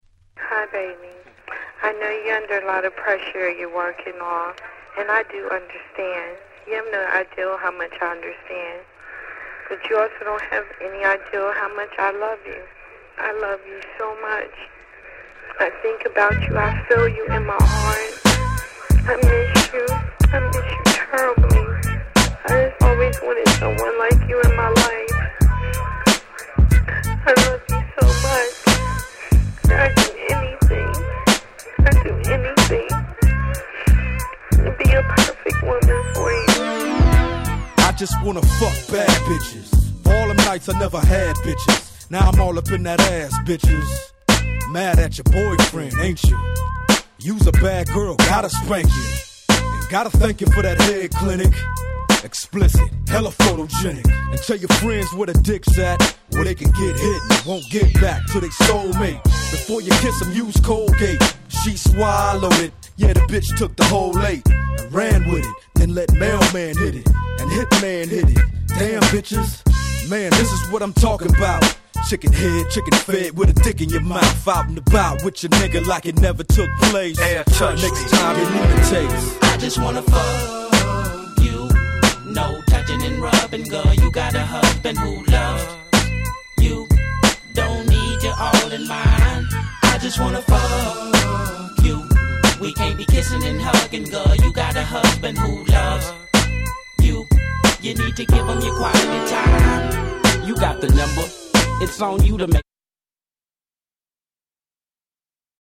G-Rap Gangsta Rap